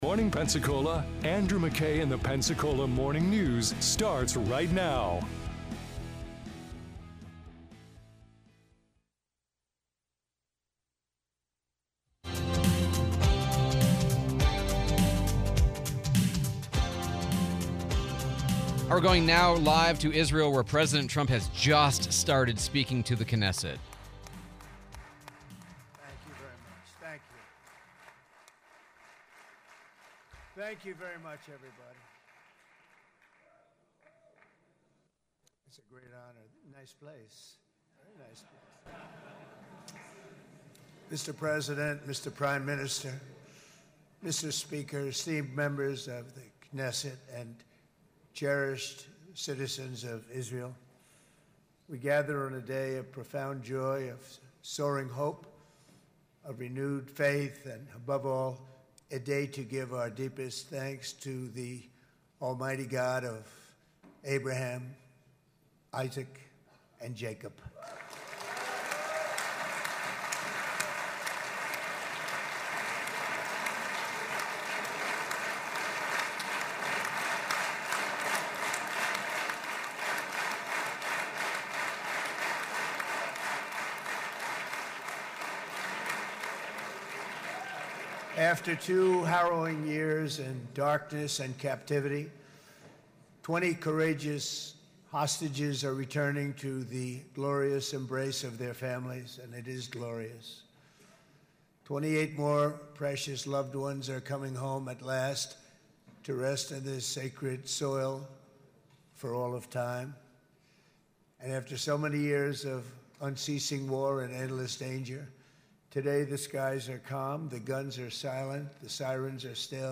Trump Speech Live